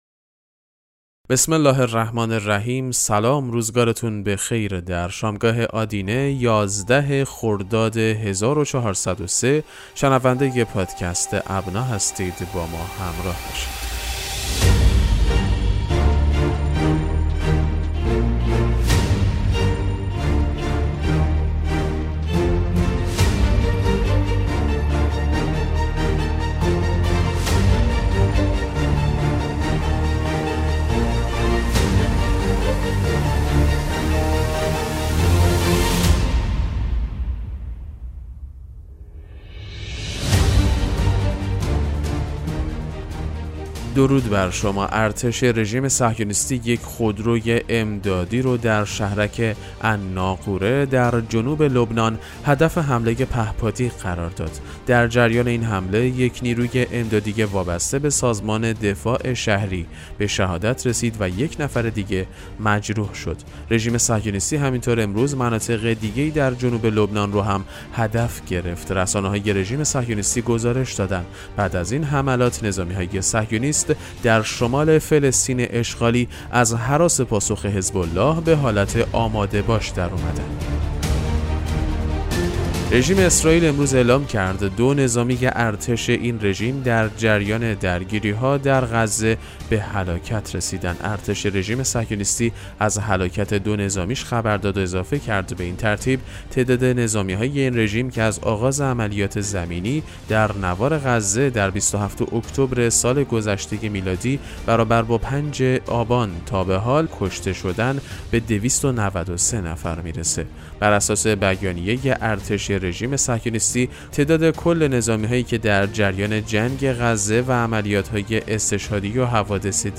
پادکست مهم‌ترین اخبار ابنا فارسی ــ 11 خرداد 1403